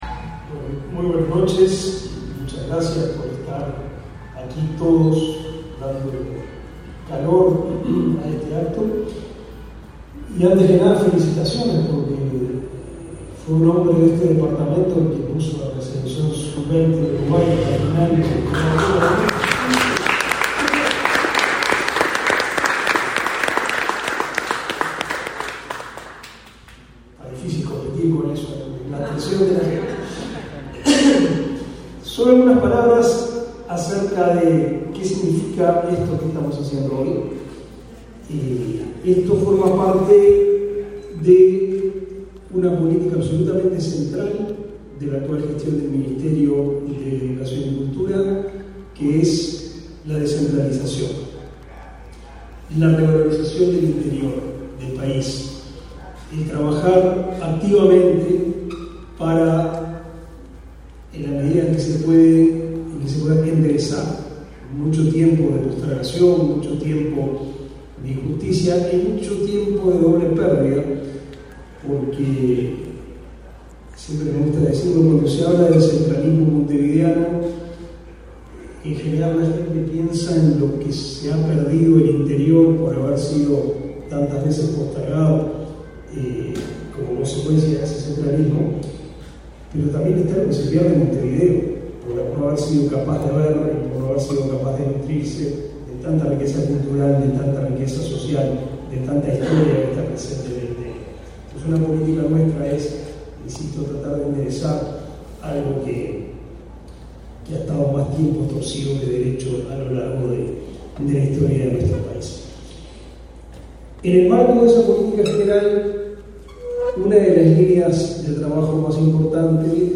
Palabras del ministro del MEC, Pablo da Silveira
Palabras del ministro del MEC, Pablo da Silveira 08/06/2023 Compartir Facebook X Copiar enlace WhatsApp LinkedIn El Ministerio de Educación y Cultura (MEC) otorgó, este 8 de junio, la nominación como Centro Cultural Nacional a la Casa de la Cultura de Paso de los Toros, en el departamento de Tacuarembó. Participó del evento el ministro Pablo da Silveira.